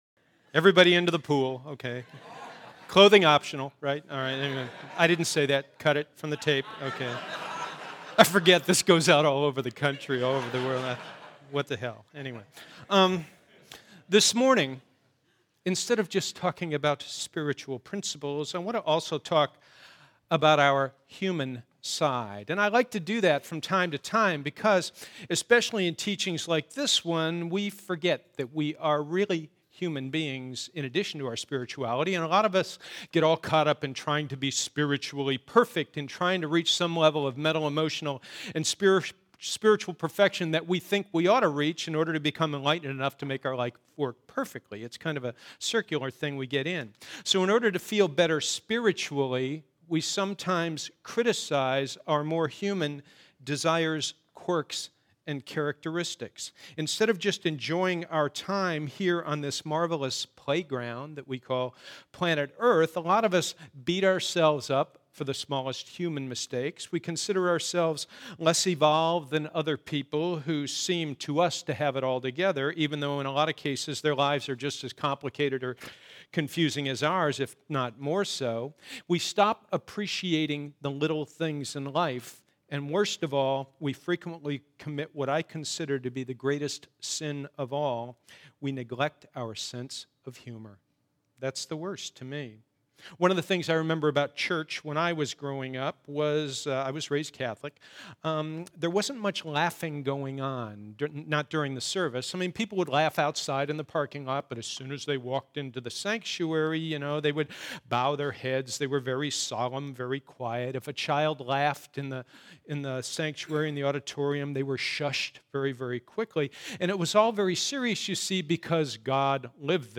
Weekly talks from our one-hour Sunday services
Our one-hour Sunday services are open and comfortable, with music, laughter, and interesting talks.